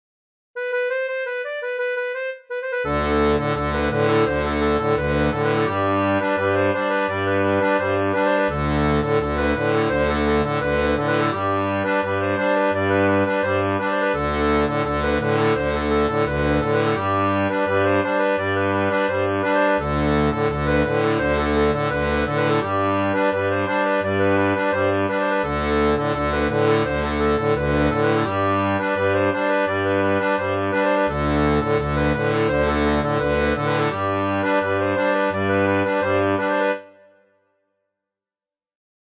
Une desgarrada portugaise pour débutant
• Une tablature pour diato à 2 rangs
Folk et Traditionnel